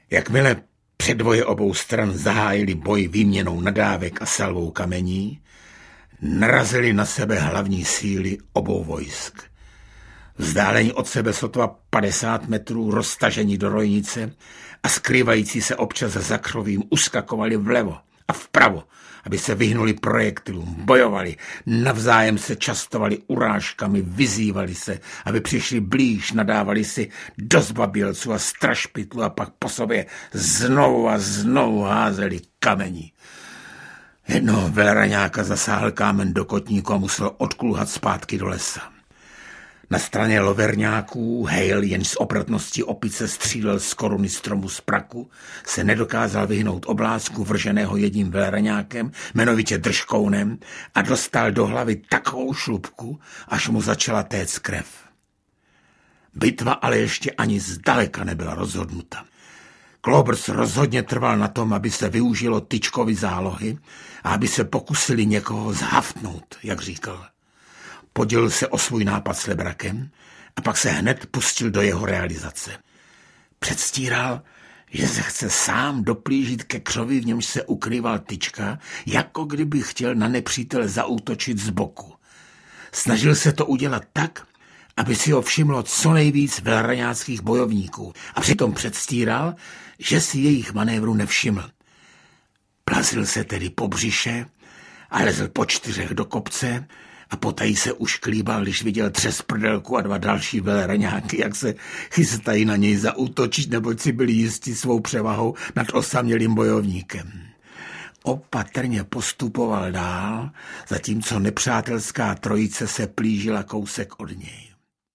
Knoflíková válka audiokniha
Luděk Munzar kouzelně vypráví příběh o nesmiřitelné válce Loverňáků a Velraňáků, dvou klukovských part ze sousedních vesnic.
Ukázka z knihy